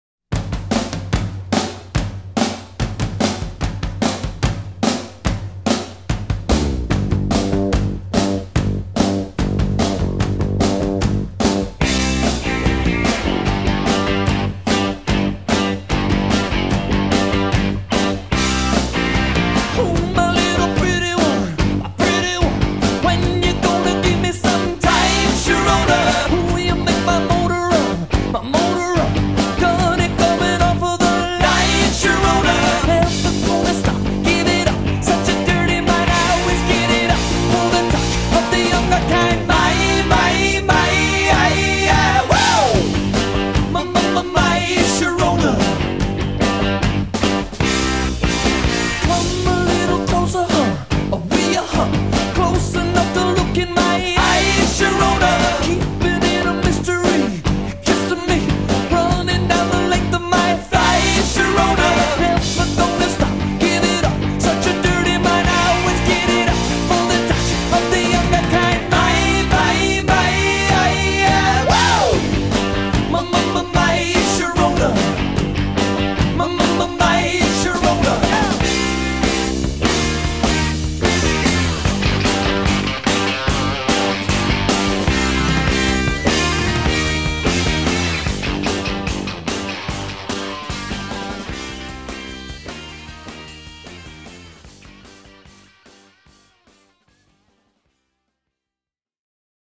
BPM138-156
Audio QualityPerfect (Low Quality)
This one is not a constant BPM; it drifts a lot.